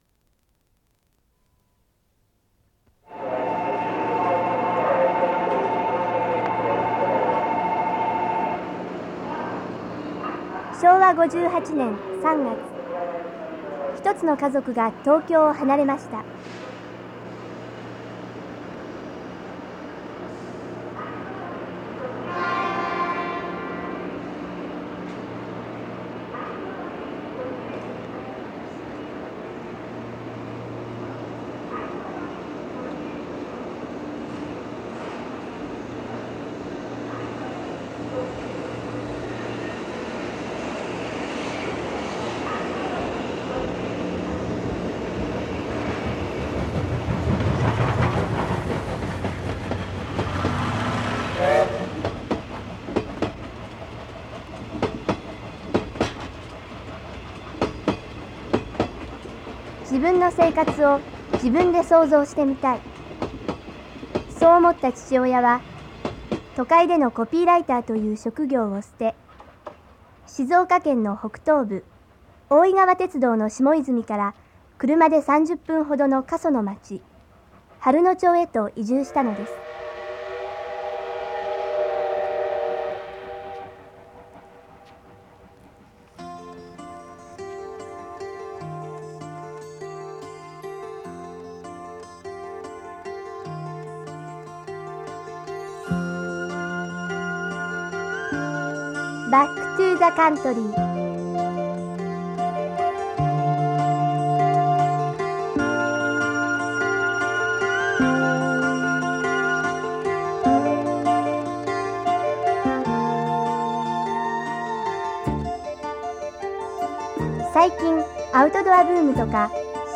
卒業制作：ラジオドキュメンタリー「Back to the country」